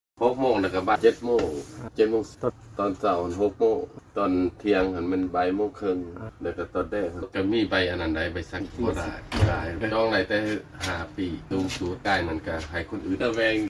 ເຊີນຟັງການສຳພາດ ຄົນຂົບລົດເມຜູ້ນຶ່ງ